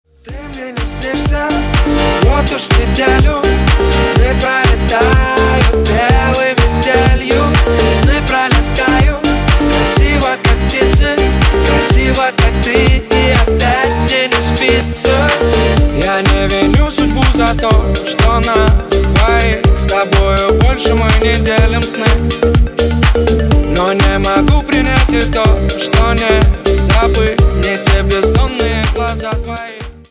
• Качество: 128, Stereo
мужской вокал
remix
dance
Стиль: Club House